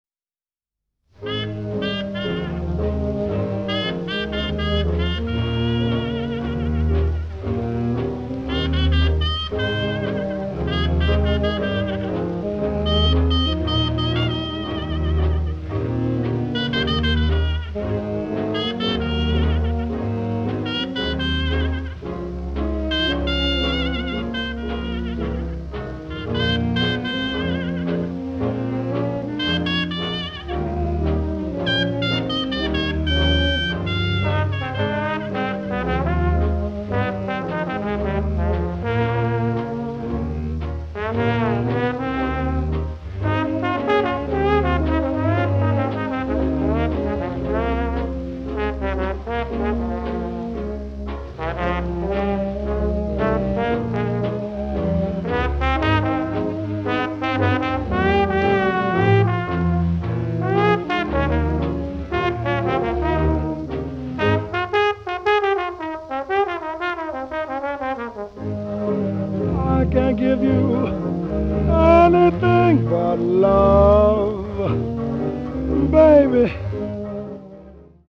which is finished out by the trombone player